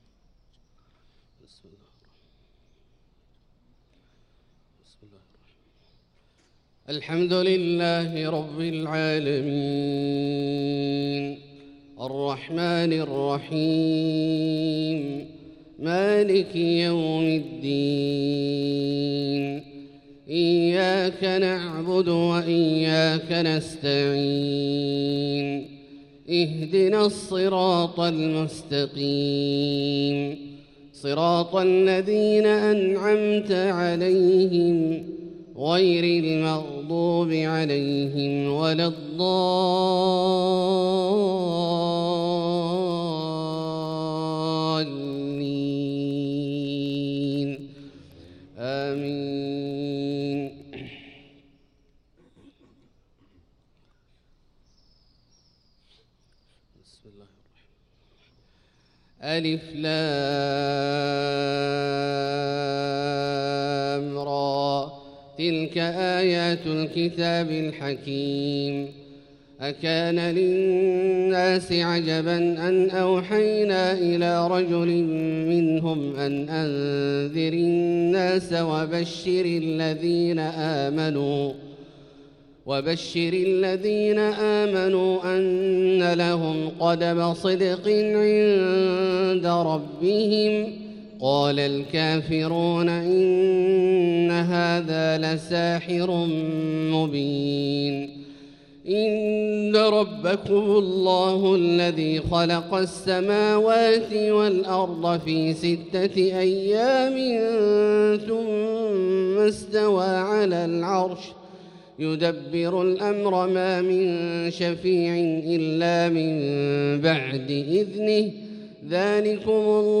صلاة الفجر للقارئ عبدالله الجهني 3 شعبان 1445 هـ
تِلَاوَات الْحَرَمَيْن .